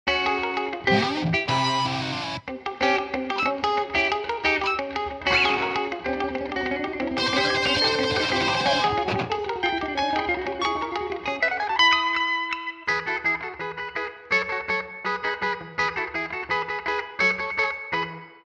Hyper Beam Sound Effects